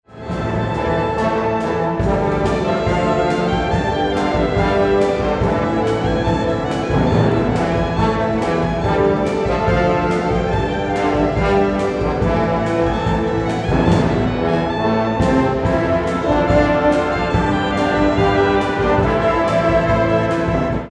Audiobeispiel eines Posaunensatzes
Audiobeispiel Posaunensatz
posaunensatz.mp3